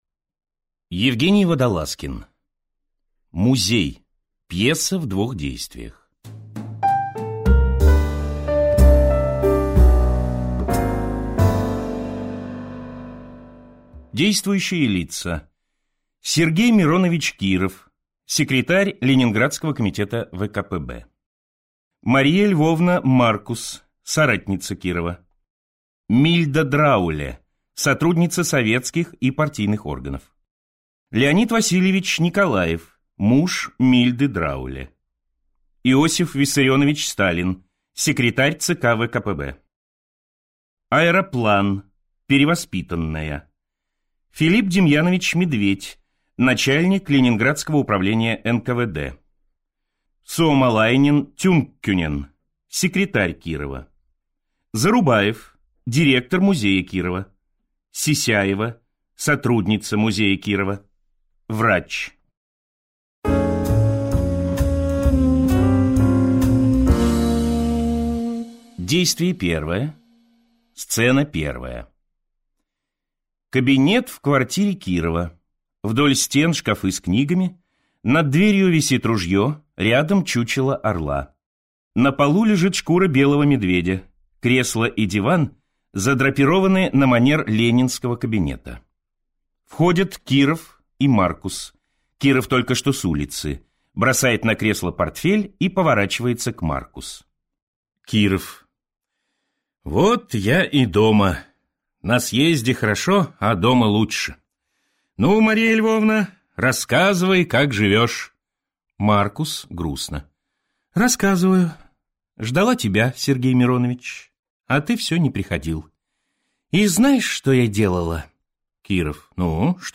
Аудиокнига Музей | Библиотека аудиокниг